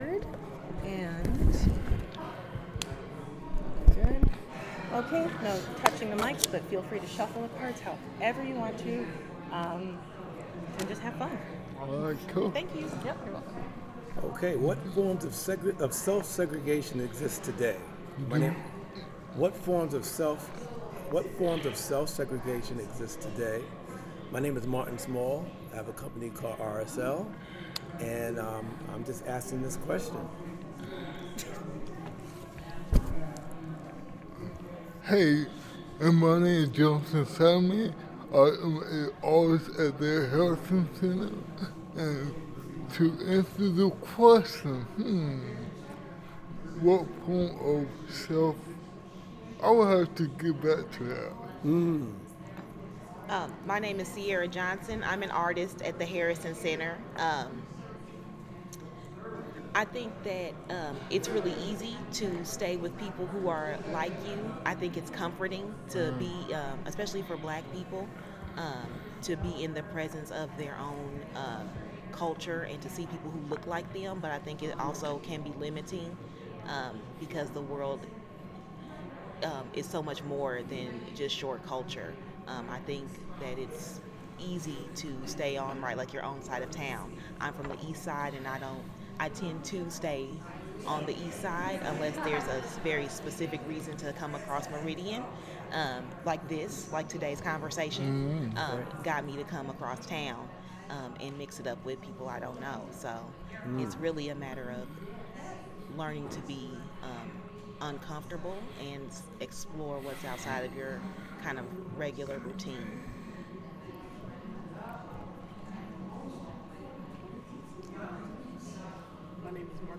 sound recording-nonmusical
Genre oral history